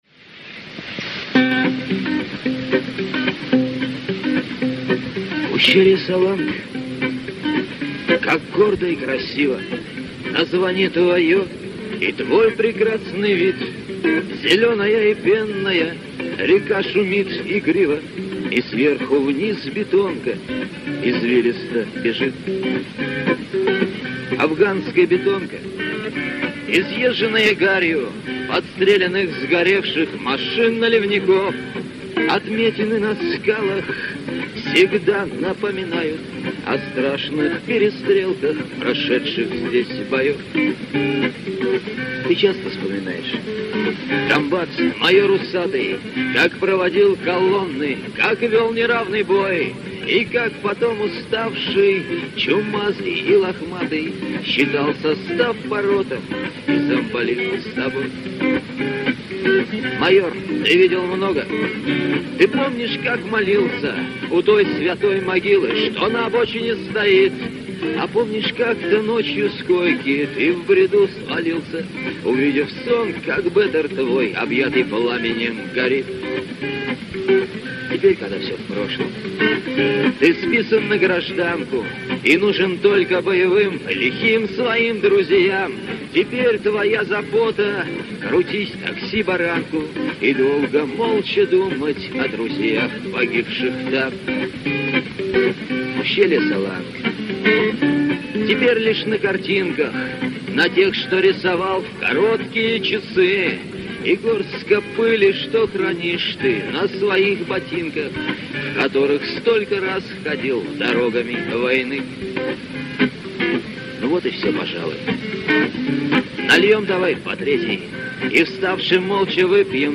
Песня советских солдат о войне в Афганистане 1979-1989 гг.